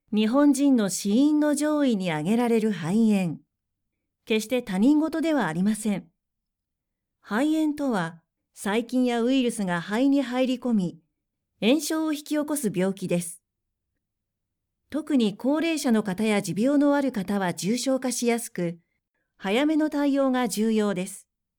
クセのない素直な声質で、明るく元気なものから落ち着いたものまで対応可能です。
– ナレーション –
落ち着いた
female59_9.mp3